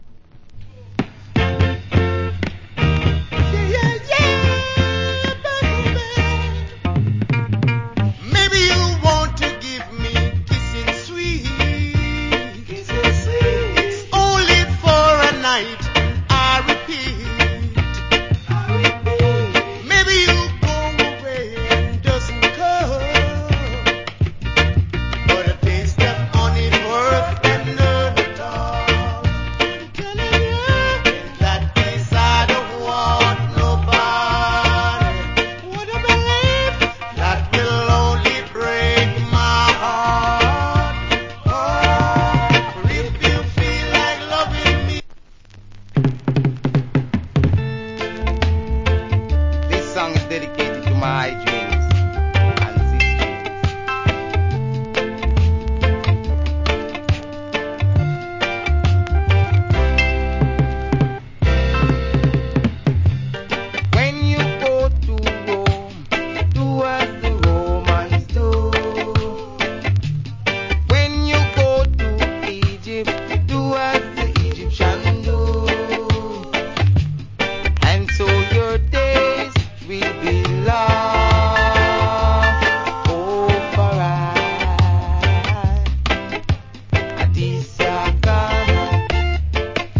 Wicked Reggae Vocal.